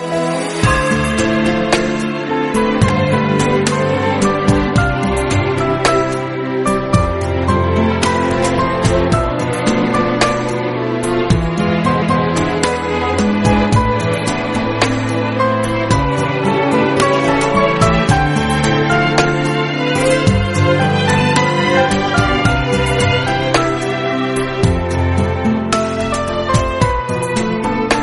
Klassik